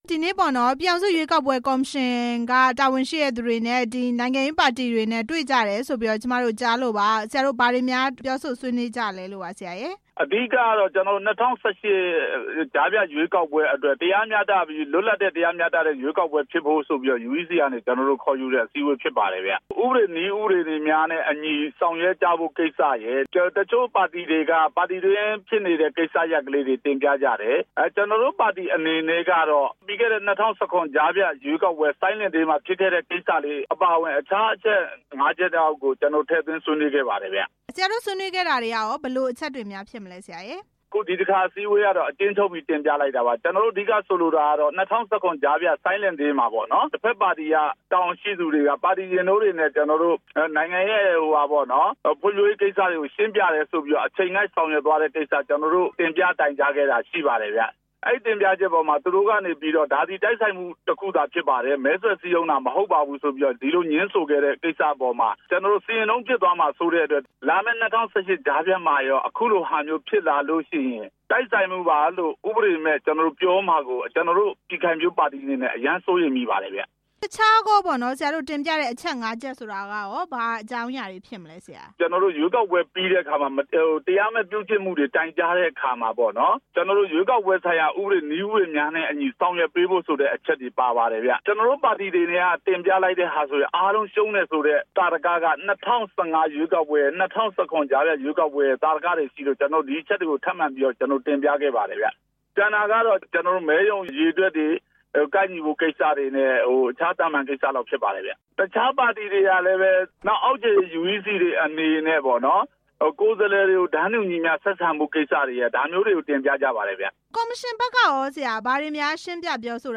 ရွေးကောက်ပွဲကော်မရှင်နဲ့ နိုင်ငံရေးပါတီတွေ တွေ့ဆုံပွဲအကြောင်း မေးမြန်းချက်